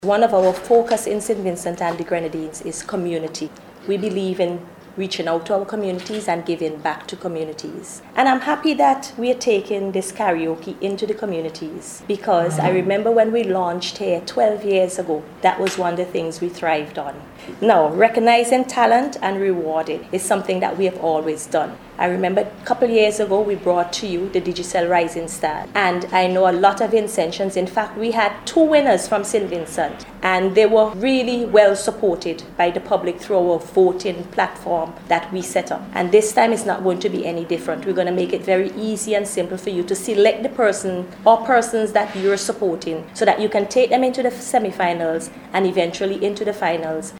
A National Karaoke Competition dubbed “Vincy Voice” was officially launched here yesterday afternoon at a media conference. The competition is being hosted by Third Eye Security Systems in collaboration with Digicel.